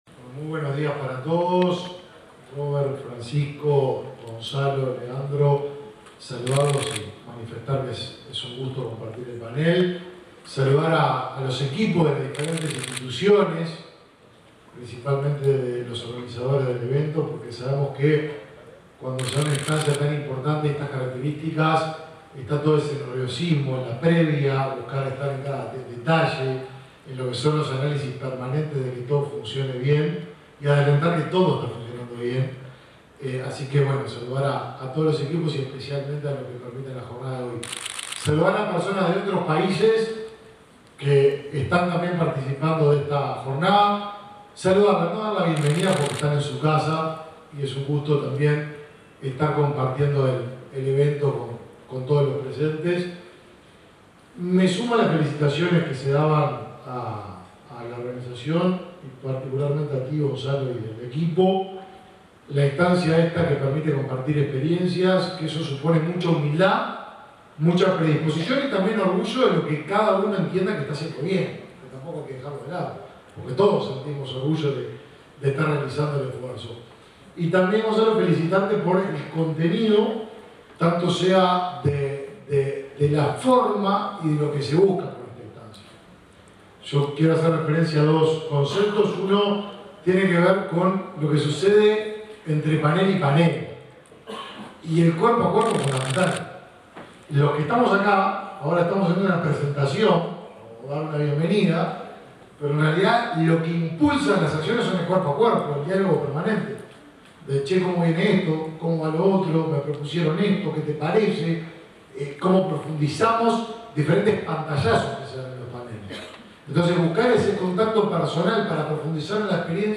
Palabras de autoridades en acto del MEC
Palabras de autoridades en acto del MEC 11/10/2023 Compartir Facebook X Copiar enlace WhatsApp LinkedIn Este miércoles 11 en Montevideo, el ministro de Desarrollo Social, Martín Lema, y el presidente de la Administración Nacional de Educación Pública (ANEP), Robert Silva, participaron en la apertura del primer congreso internacional sobre educación inclusiva.